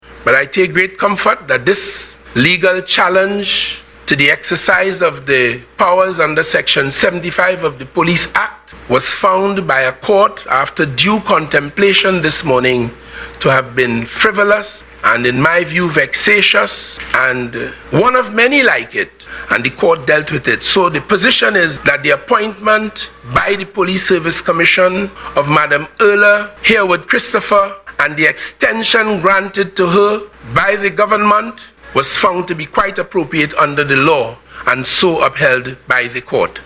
Mr Hinds welcomed the ruling during a very brief media conference held today.